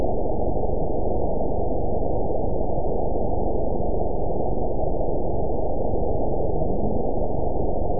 event 911178 date 02/13/22 time 13:43:09 GMT (3 years, 3 months ago) score 8.15 location TSS-AB05 detected by nrw target species NRW annotations +NRW Spectrogram: Frequency (kHz) vs. Time (s) audio not available .wav